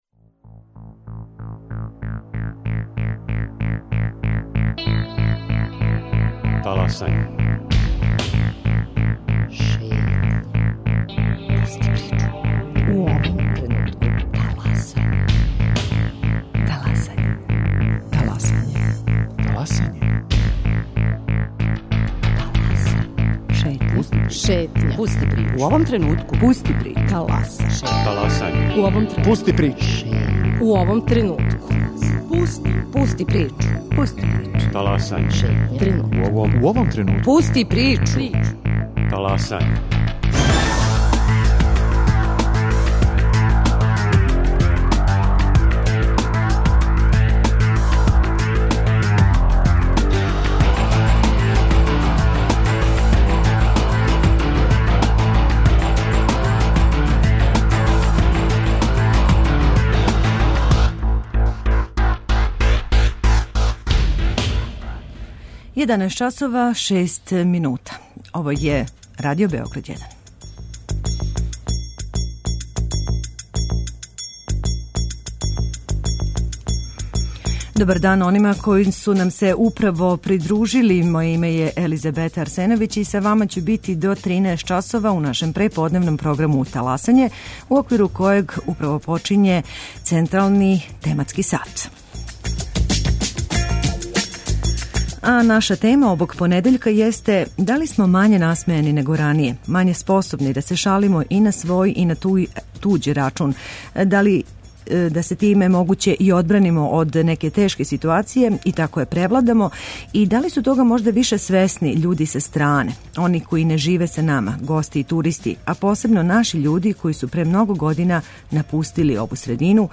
Представићемо вам три хумористичко-сатирична конкурса у Бања Луци, Чајетини и Крушевцу (неки су управо завршени, а неки су још у току) и кроз разговор са нашим гостима покушаћемо да дамо одговоре на горе постављена питања.